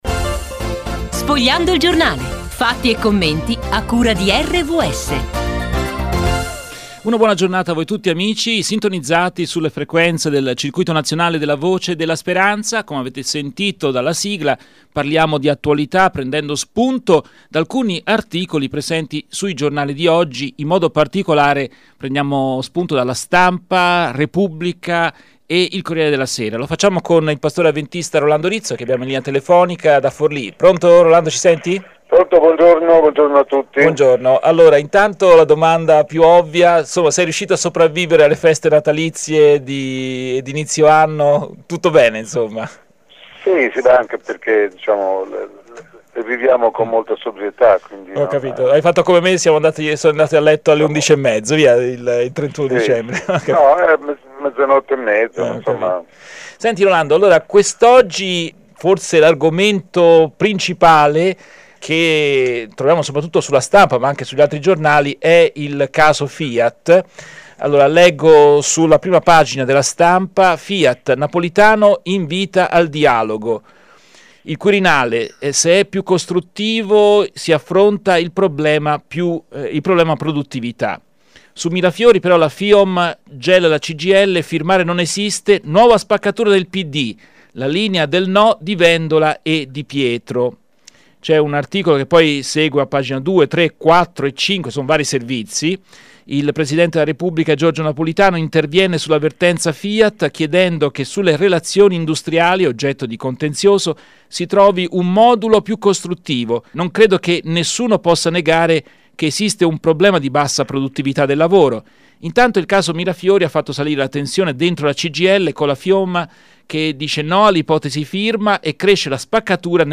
Breve rassegna stampa